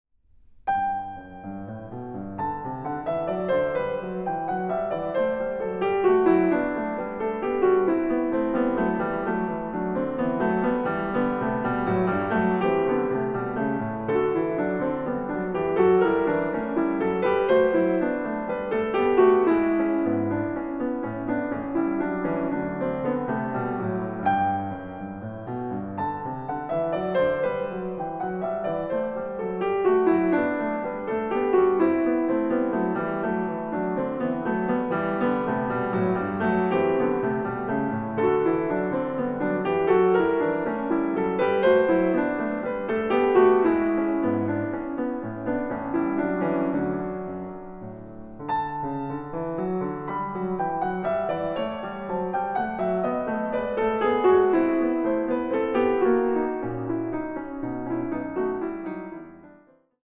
Piano
Recording: Jesus-Christus-Kirche Berlin-Dahlem, 2024